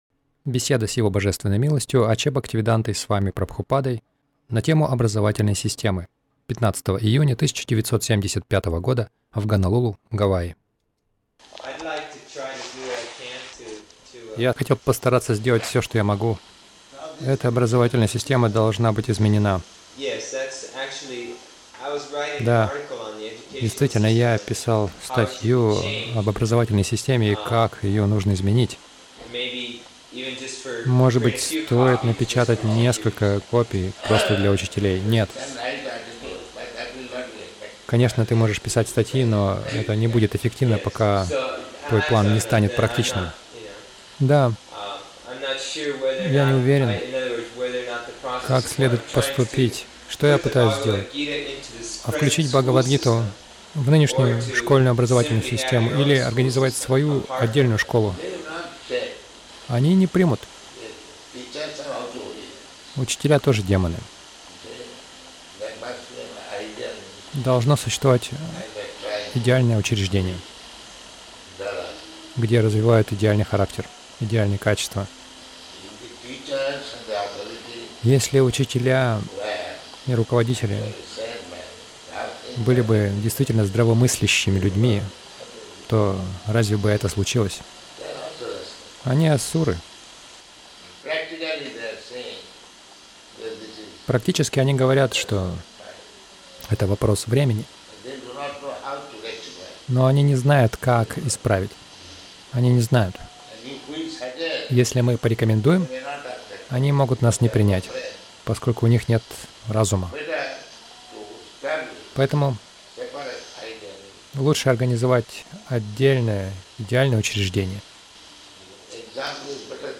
Беседа — Образовательная система варнашрамы
Милость Прабхупады Аудиолекции и книги 15.06.1975 Беседы | Гонолулу Беседа — Образовательная система варнашрамы Загрузка...